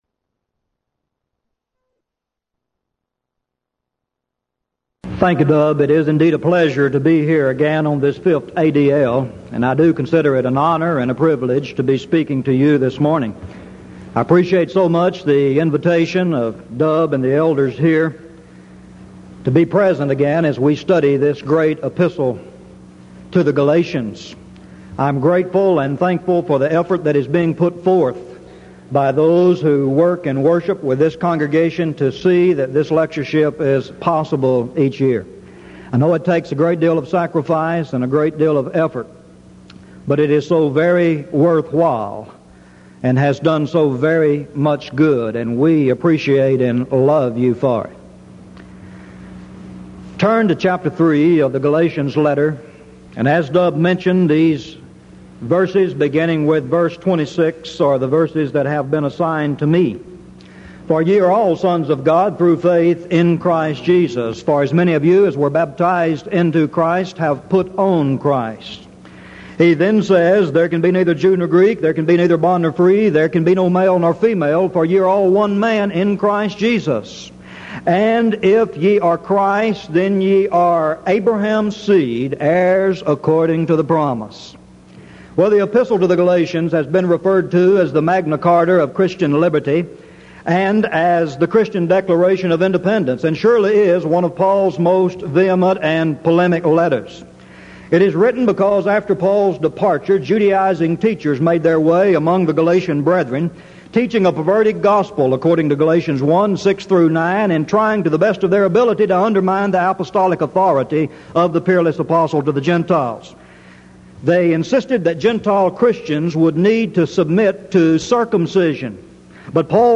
Event: 1986 Denton Lectures
If you would like to order audio or video copies of this lecture, please contact our office and reference asset: 1986Denton10